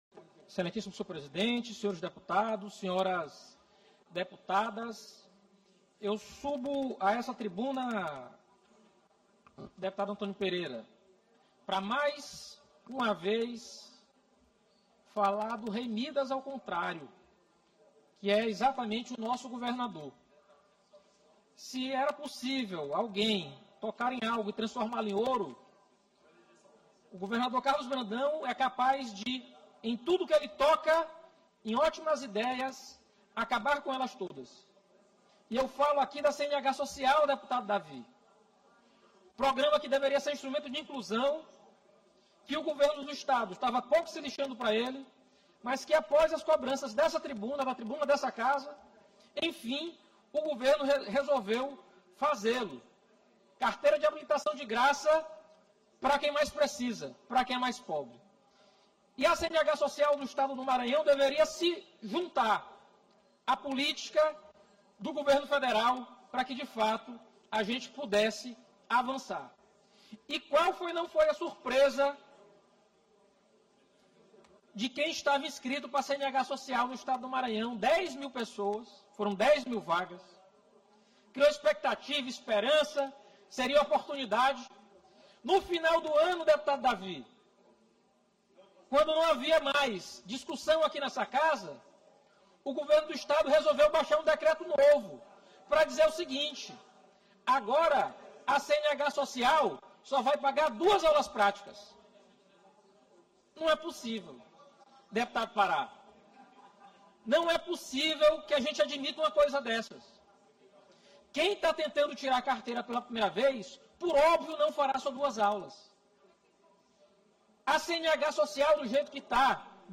Pequeno Expediente Carlos Lula – Assembleia Legislativa do Maranháo
Início -> Discursos